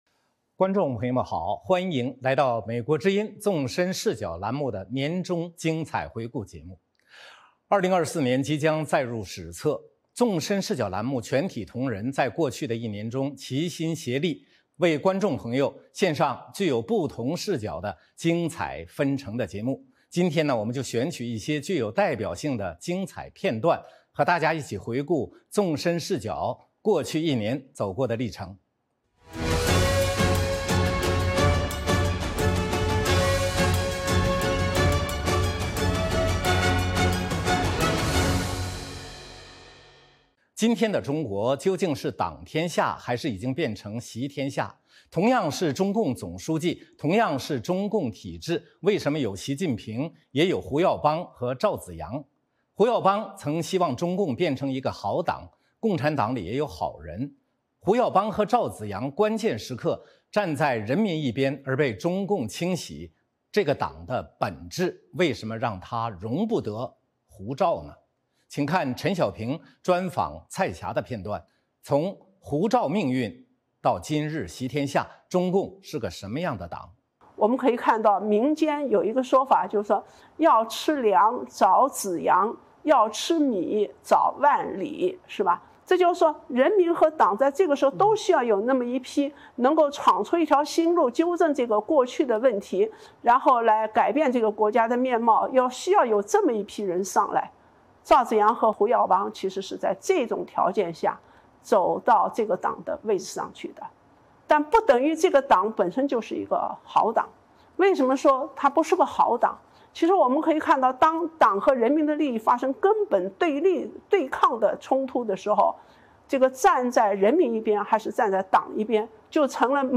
我们选取一些具有代表性的精彩片段，和大家一起回顾纵深视角过去一年走过的历程。 《纵深视角》节目进行一系列人物专访，受访者所发表的评论不代表美国之音的立场